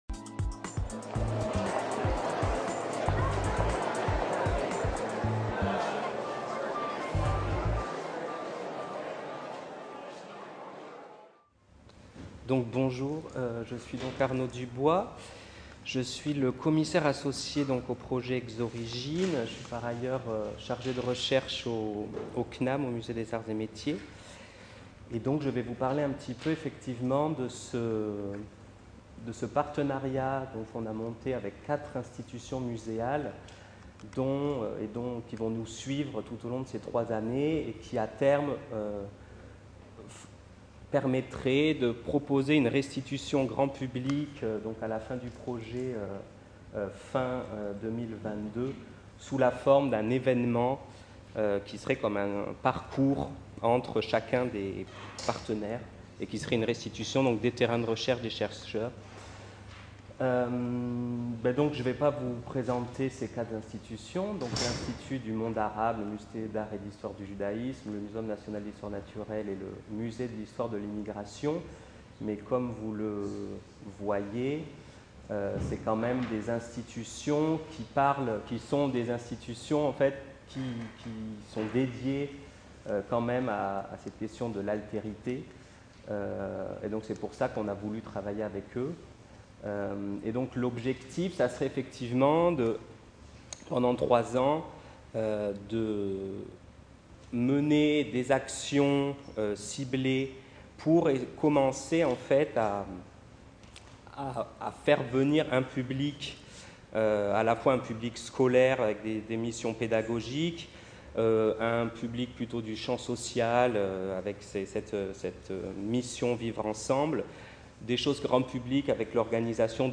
Jeudi 28 mars 2019, Jardin des Plantes, Paris INTRODUCTION DU WORKSHOP